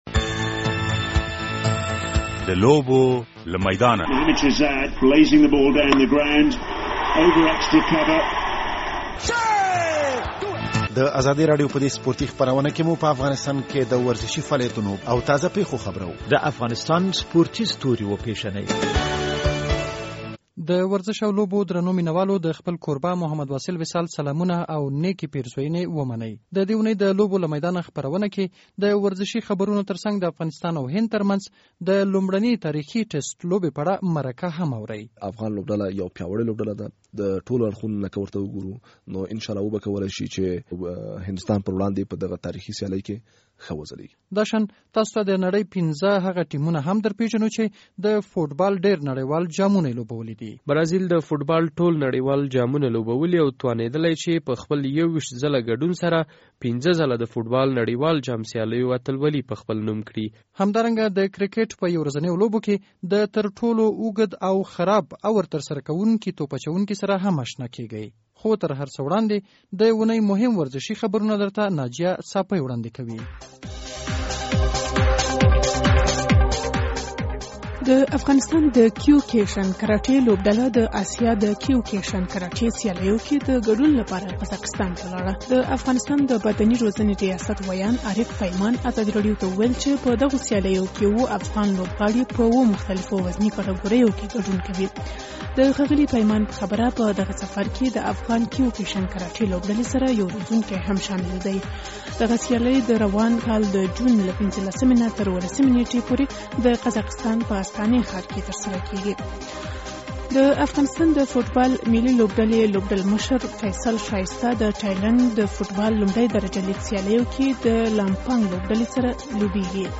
د ازادي راډیو په اوونیزه ورزشي خپرونه «د لوبو له میدانه» کې؛ د اوونۍ مهم ورزشي خبرونه.